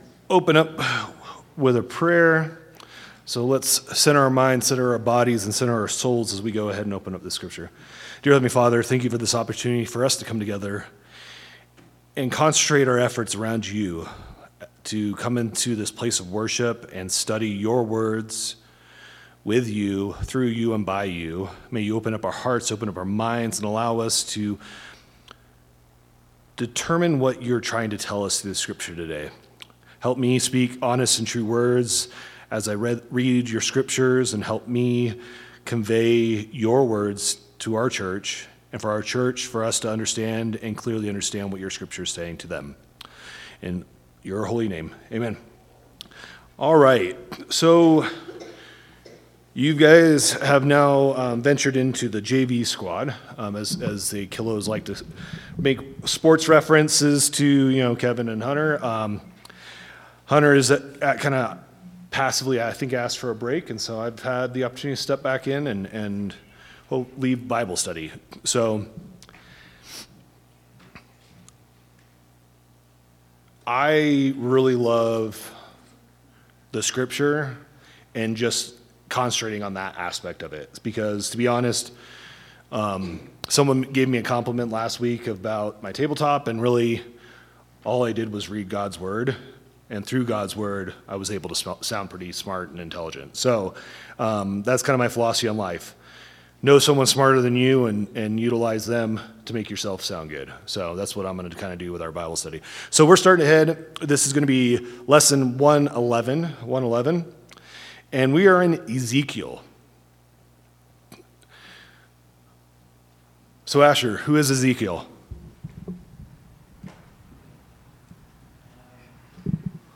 Bible Class 10/26/2025 - Bayfield church of Christ
Sunday AM Bible Class